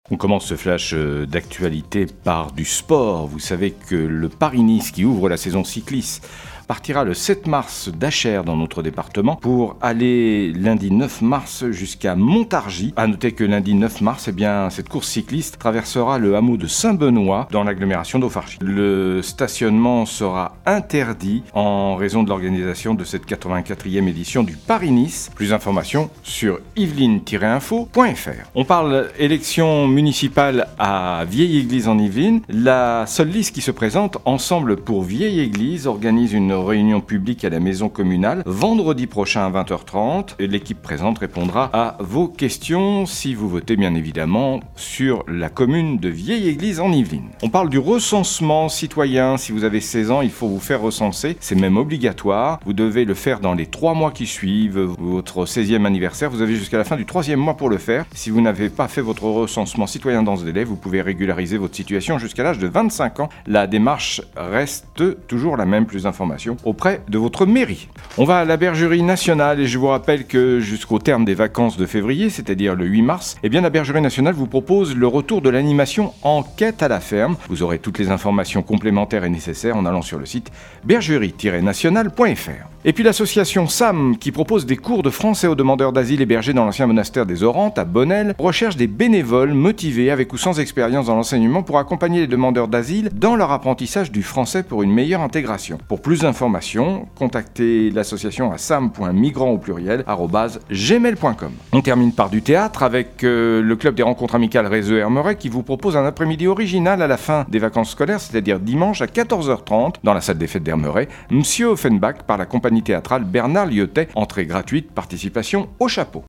03.03-flash-local-matin.mp3